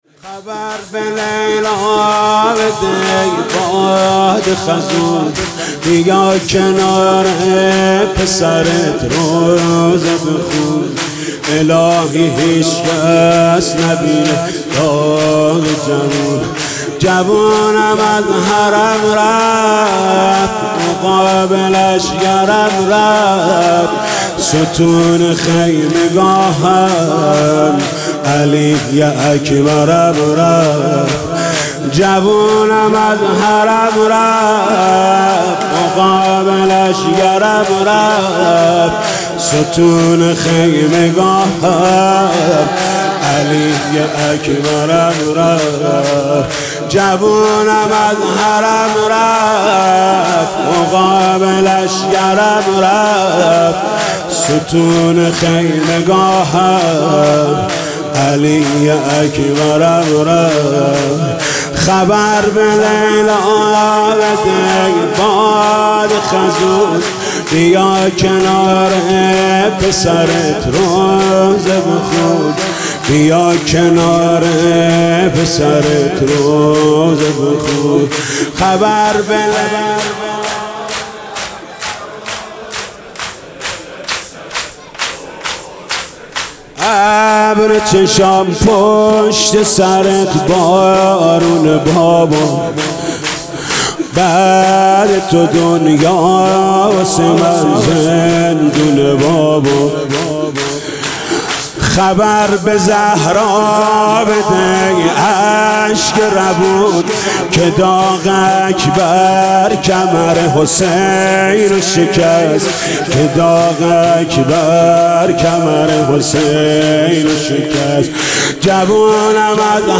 «محرم 1396» (شب هشتم) شور: خبر به لیلا بده ای باد خزون
«محرم 1396» (شب هشتم) شور: خبر به لیلا بده ای باد خزون خطیب: حاج محمود کریمی مدت زمان: 00:03:01